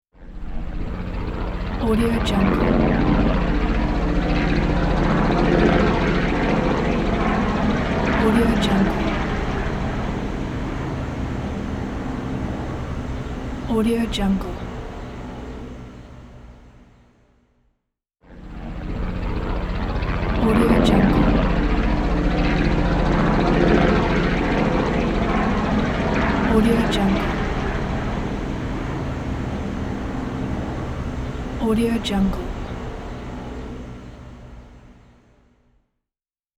دانلود افکت صدای پرواز هلیکوپتر
Sample rate 16-Bit Stereo, 44.1 kHz
Looped No